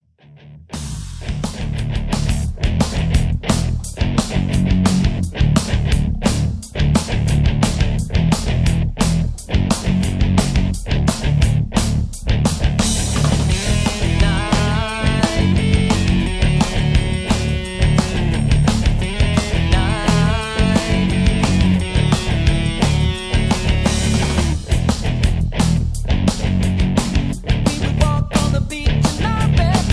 karaoke, mp3 backing tracks
rock, heavy metal, grunge